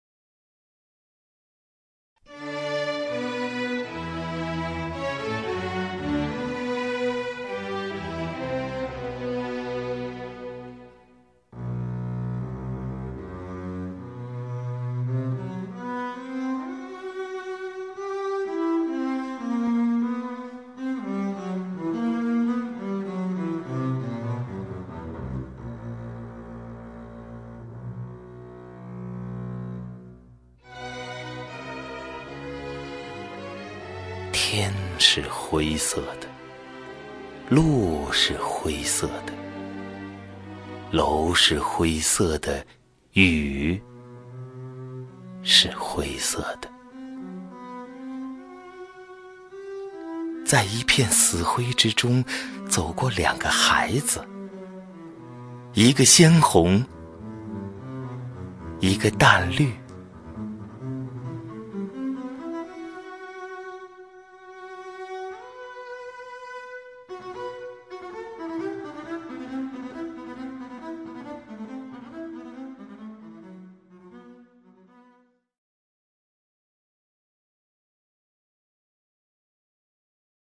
赵屹鸥朗诵：《感觉》(顾城) 顾城 名家朗诵欣赏赵屹鸥 语文PLUS